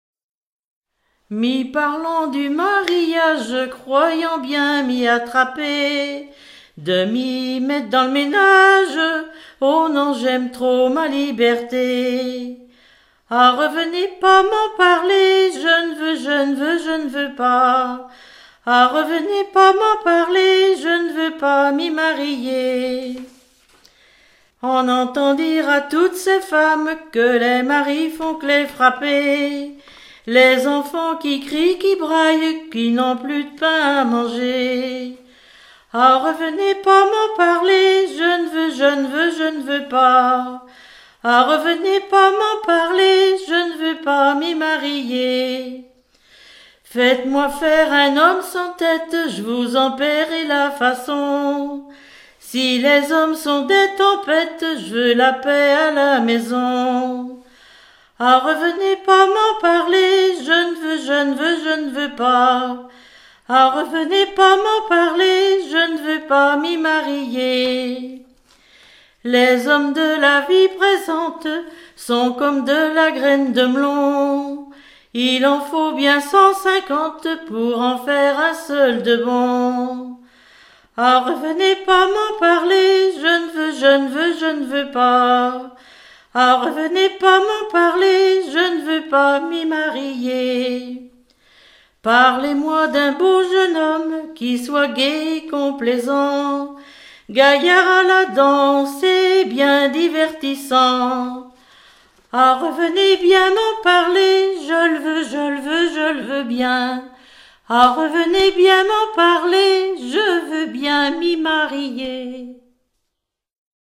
chanteur(s), chant, chanson, chansonnette
Genre strophique